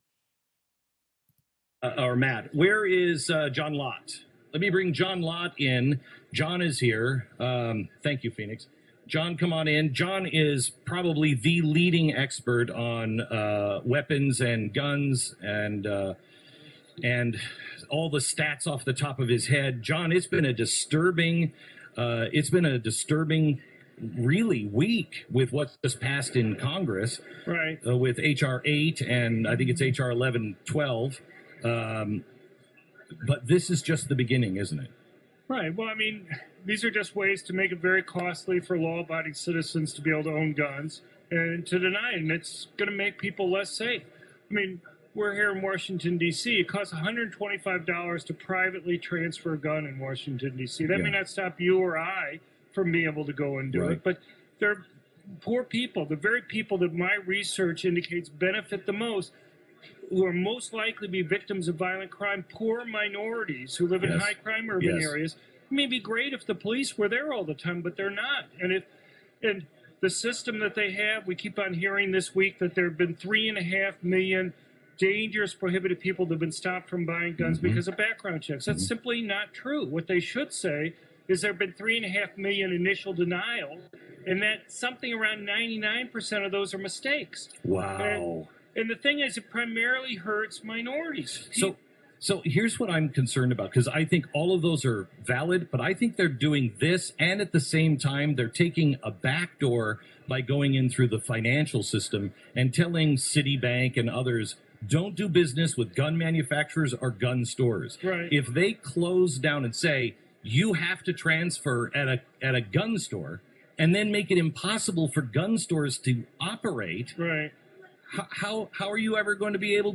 Dr. John Lott spoke with Glenn Beck at CPAC 2019 about the dangers of various gun-control laws and how these laws actually prevent those most at risk of being victims of violent crime, from being able to protect themselves.